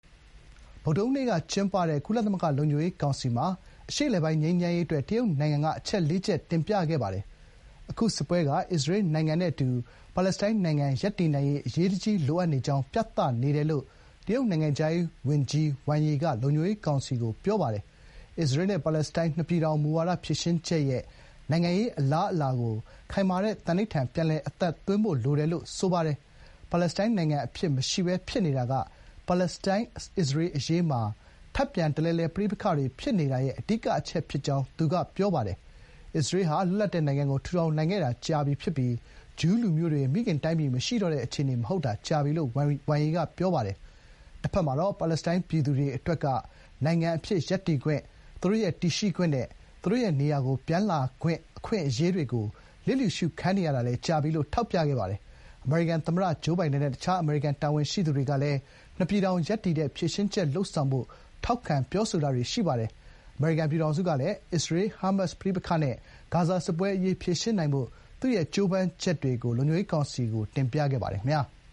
နိုဝင်ဘာ ၂၉ ရက် (ဗုဒ္ဓဟူးနေ့) က ကျင်းပတဲ့ ကုလသမဂ္ဂလုံခြုံရေးကောင်စီမှာ တရုတ်နိုင်ငံ နိုင်ငံခြားရေးဝန်ကြီး ဝမ်ယီက အရှေ့အလယ်ပိုင်းငြိမ်းချမ်းရေးအတွက် အချက် (၄) ချက်ကို တင်ပြစဥ်။